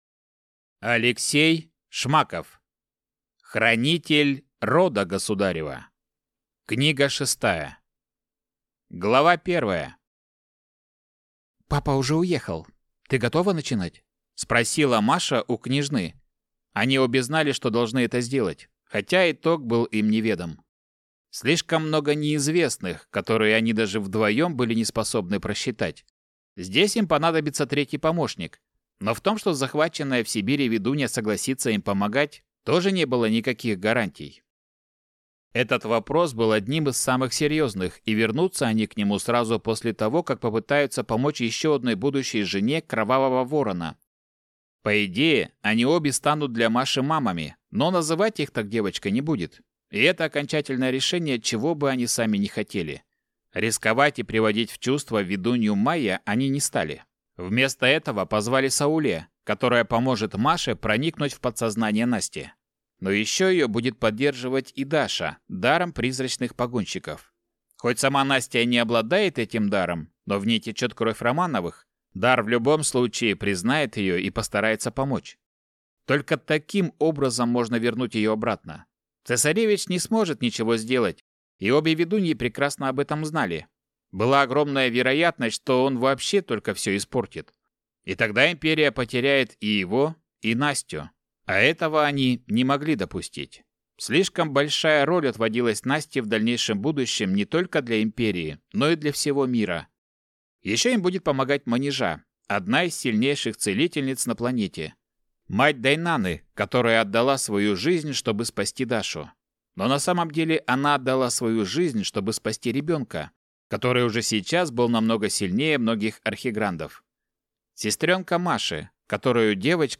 Аудиокнига Хранитель рода государева 6 | Библиотека аудиокниг